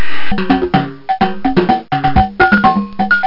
drum1.mp3